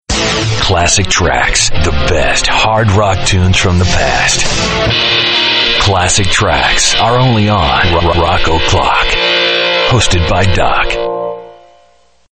RADIO IMAGING / ROCK ALTERNATIVE /